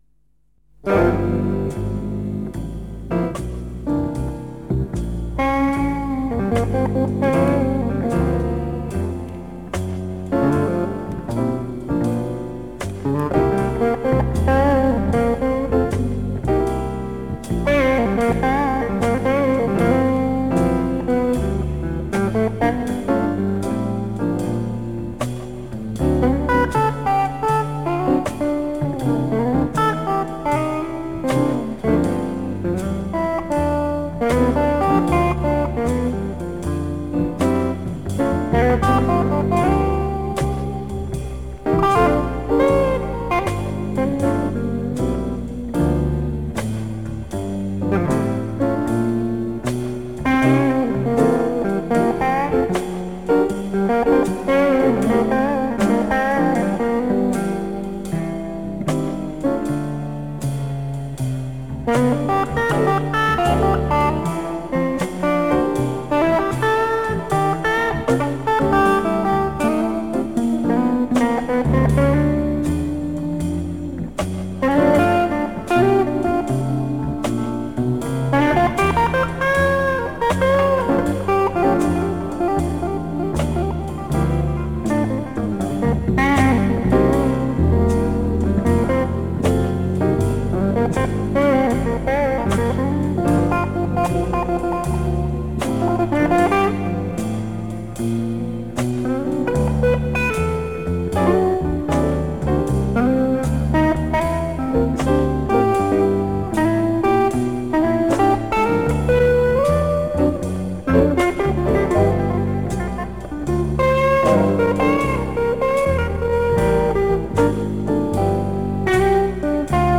Slow Blues in G